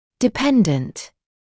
[dɪ’pendənt][ди’пэндэнт]зависящий (от); материально зависимый; зависимый